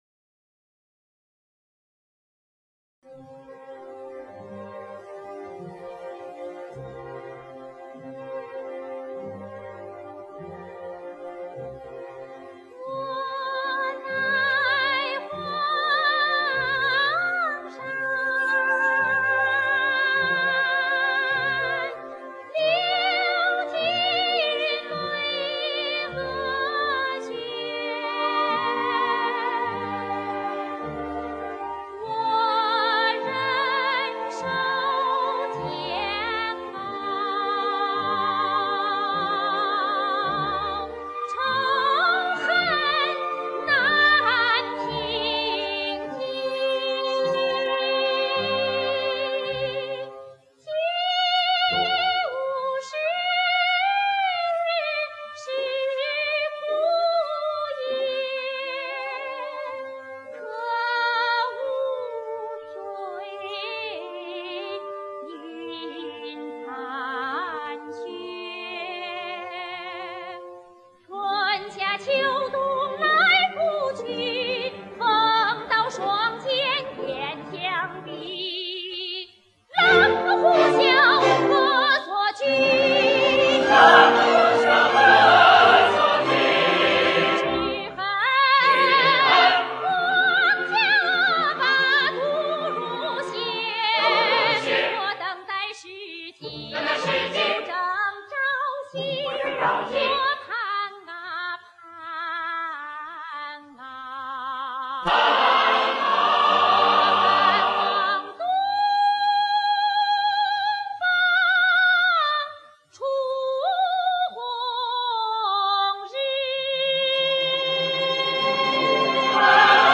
后两版录音的部分或全部唱段都有CD版，唯独1965年的首版录音始终躺在胶木唱片上。
急，只好网友自己上手翻录了。